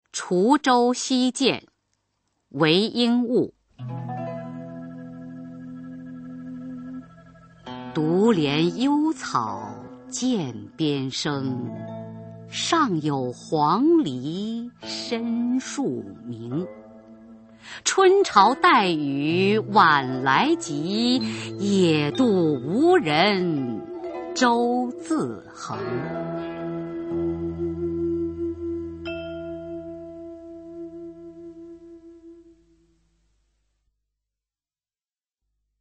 [隋唐诗词诵读]韦应物-滁州西涧 配乐诗朗诵